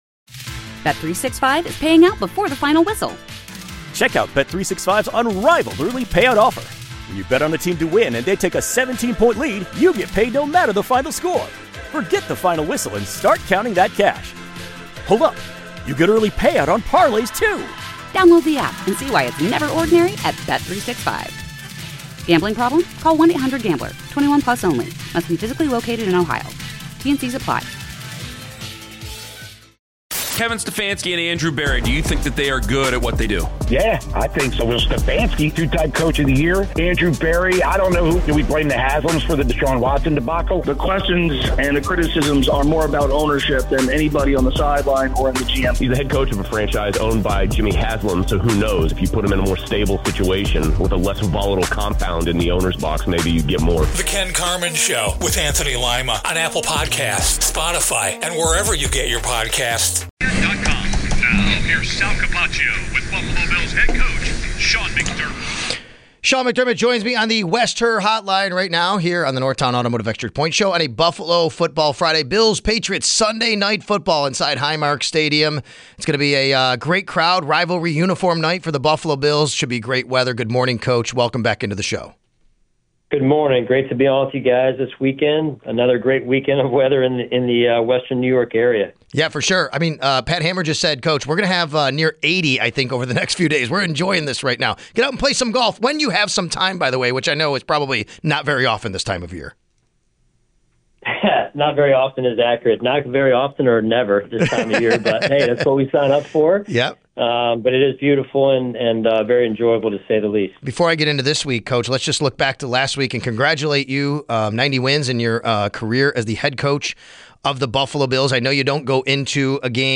Best Interviews on WGR: Sep. 29-Oct. 3